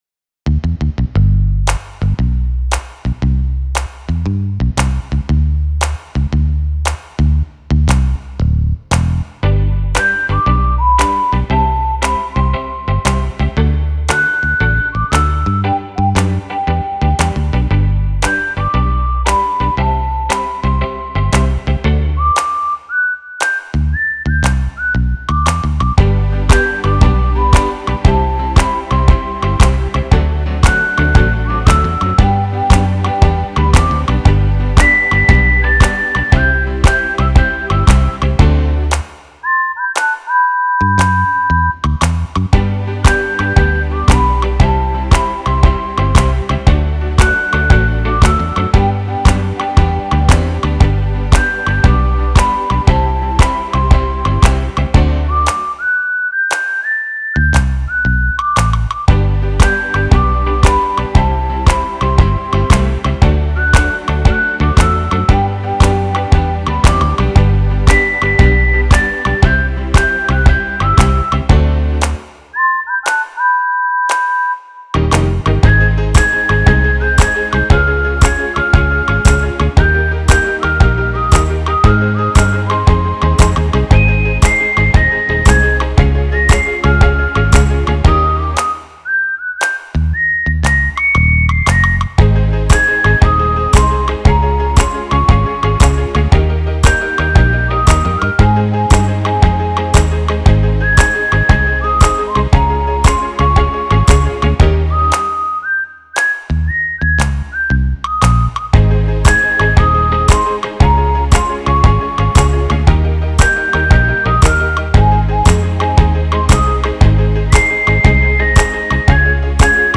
Category Archives: Country
thPNTPDZ8W  Download Instrumental